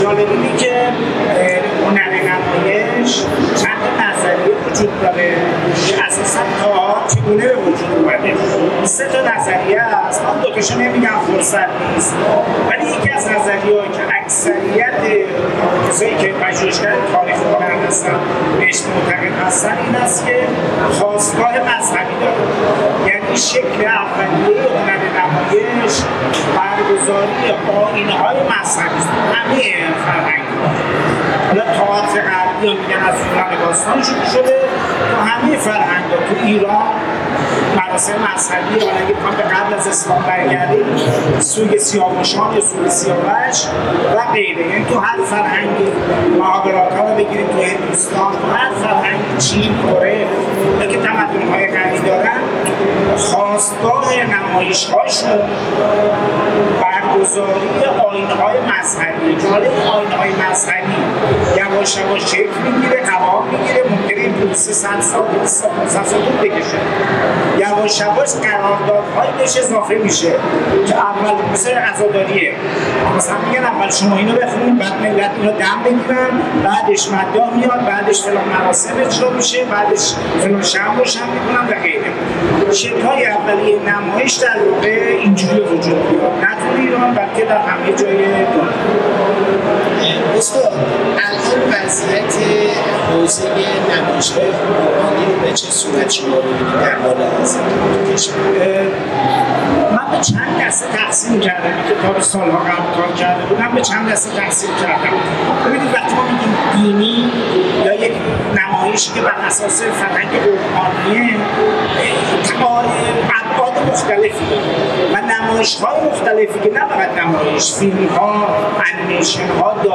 کارشناس و مدرس هنر: